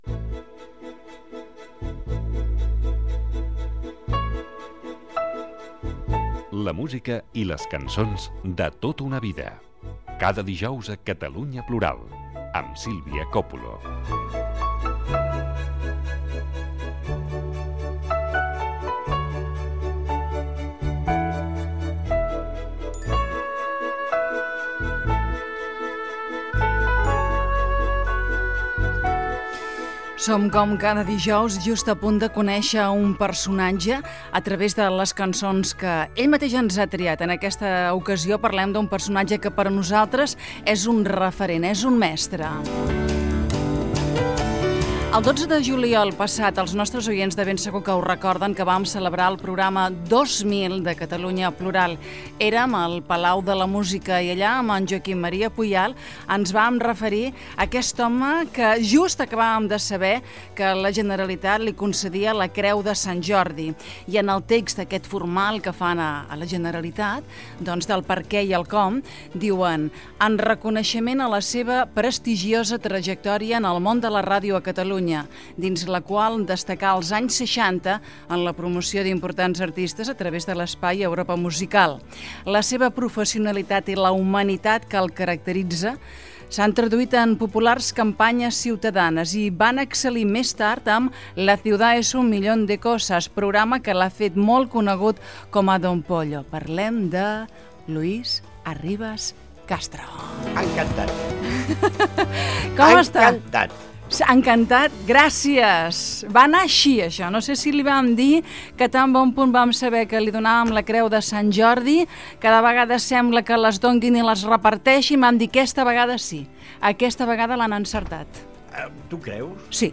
Indicatiu de la secció "La música i les cançons de tota una vida". Presentació i entrevista a Luis Arribas Castro, a qui se li ha concedit una Creu de Sant Jordi. Records de la seva trajectòria professional.
Info-entreteniment